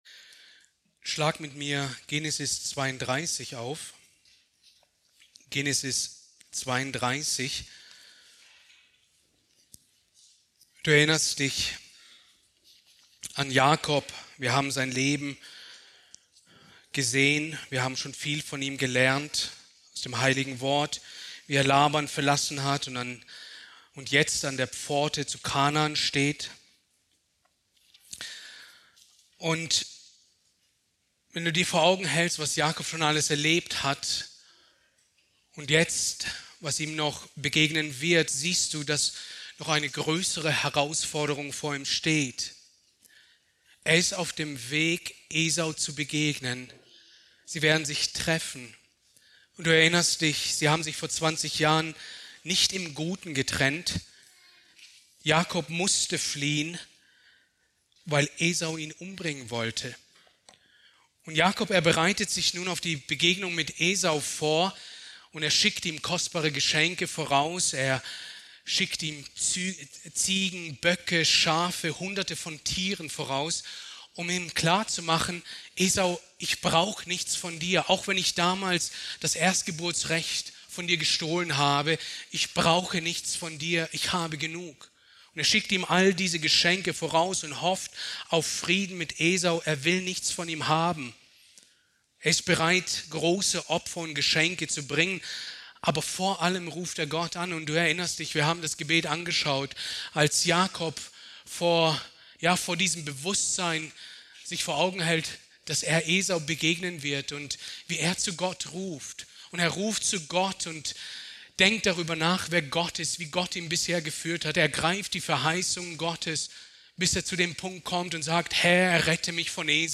Predigt aus der Serie: "Genesis"